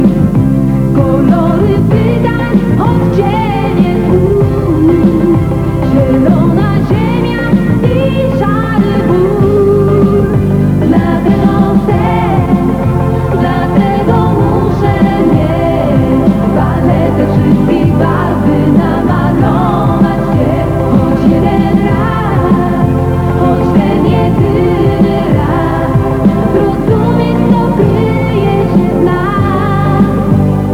piosenka z kasety, śpiewa kobieta